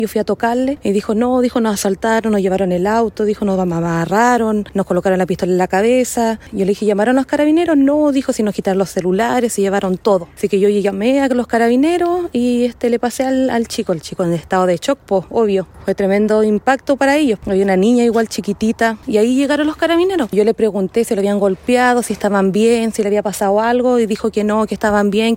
Una testigo de la situación relató que tras el escape de los ladrones fue a tocar la puerta, donde se enteró de lo que había ocurrido con sus colindantes.